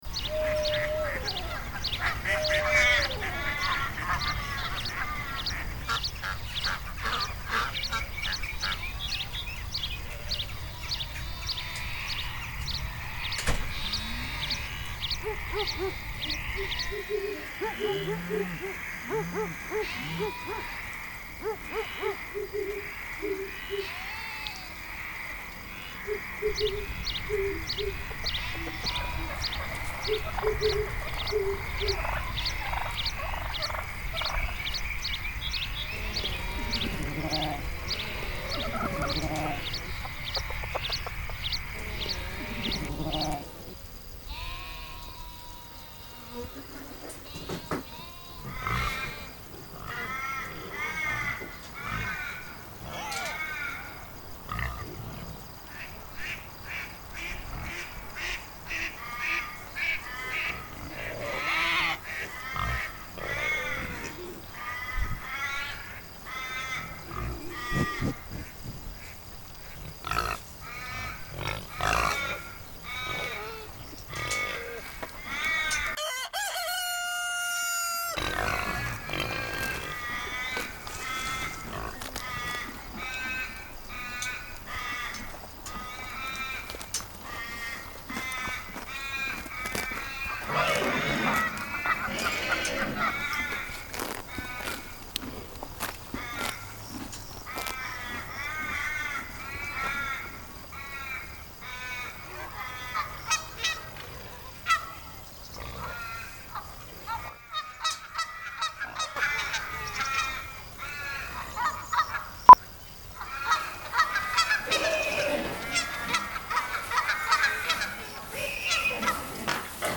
Relaxing Farm Animal Ambience Sounds ASMR - Cows, Chickens, Horses, Goats, Pigs, Ducks, Sheep, Rooster, Turkey, Bees, Dogs, Frogs and Crickets